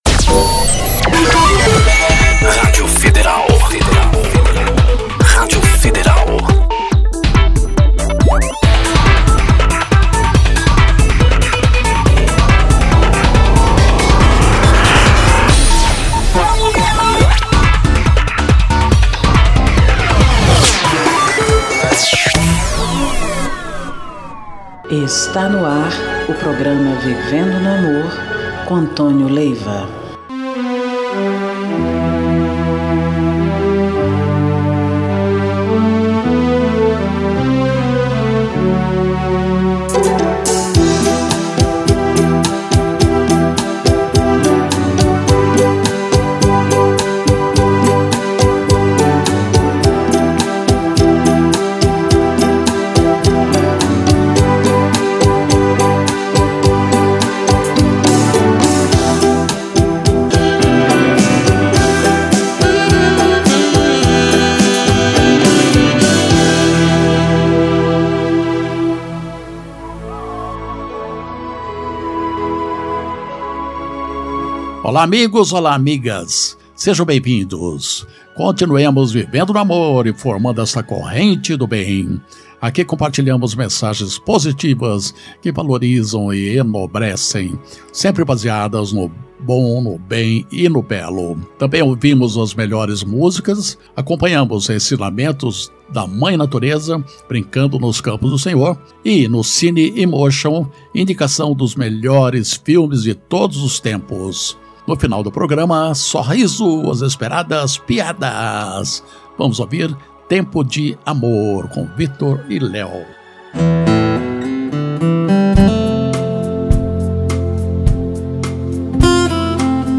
MÚSICAS E MENSAGENS